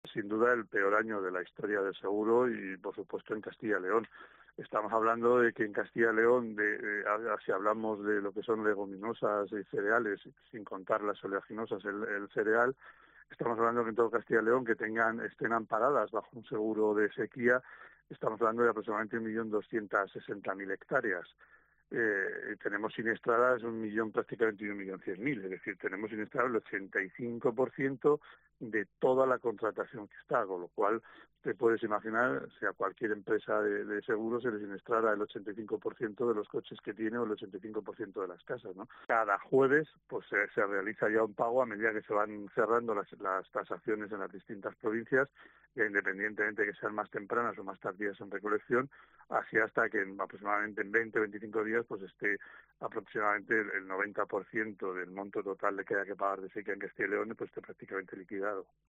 5:00H | 11 FEB 2026 | BOLETÍN